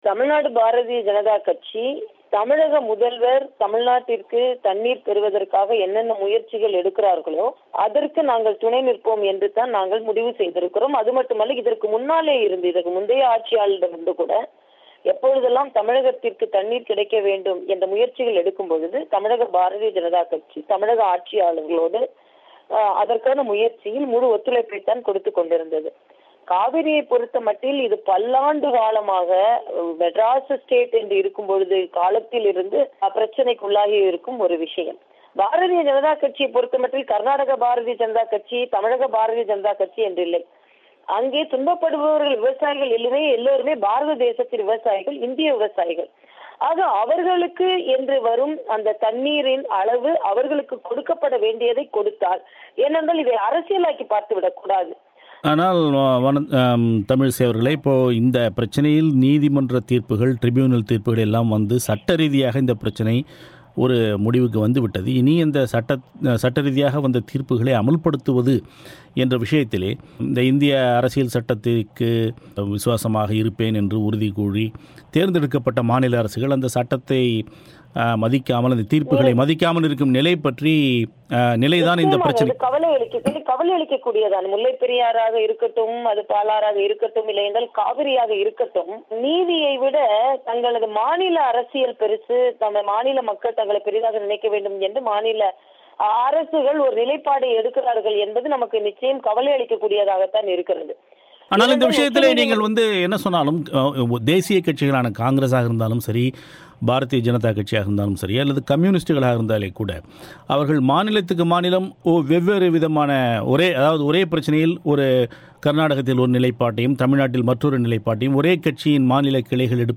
காவிரி நீர்ப்பிரச்சினையில், கர்நாடகத்தை ஆளும் காங்கிரஸ்கட்சியும், முக்கிய எதிர்க்கட்சியான பாஜகவும் அங்கு ஒருமித்த கருத்தை எடுத்திருக்கும் நிலையில், தமிழ்நாட்டின் பாஜக இந்த விஷயத்தில் என்ன நிலைப்பாட்டை எடுக்கிறது என்று பிபிசி தமிழோசையிடம் கருத்து தெரிவித்தார் தமிழக பாஜக பொதுச்செயலர் தமிழிசை சௌந்தர்ராஜன்